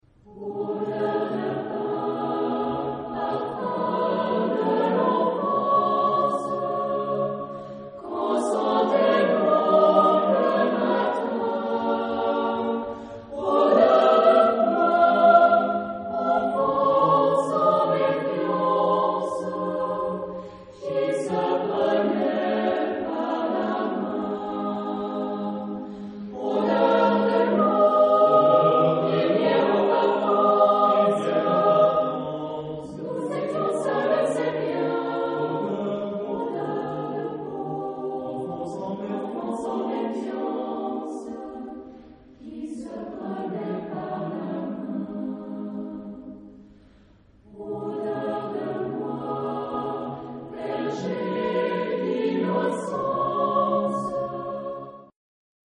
Genre-Style-Forme : Chanson ; Profane ; contemporain
Type de choeur : SATB  (4 voix mixtes )
Tonalité : do majeur ; do mineur